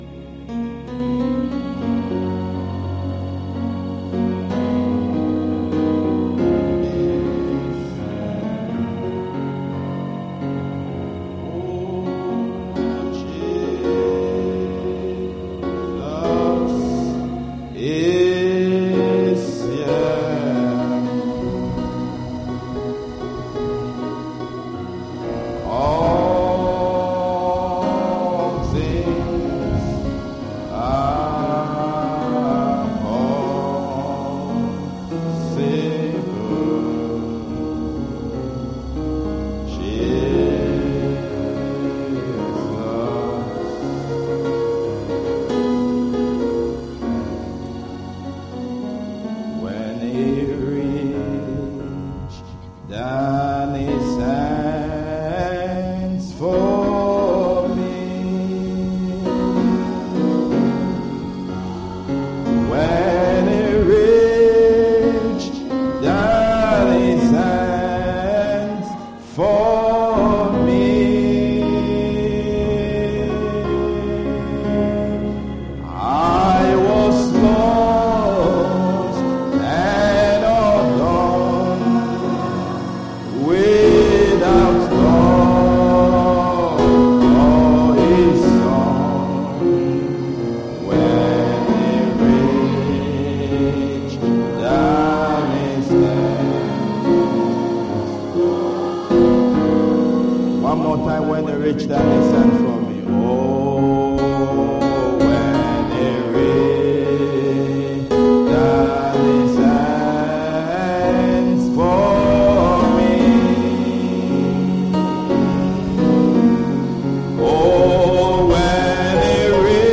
Sunday Afternoon Service 06/10/24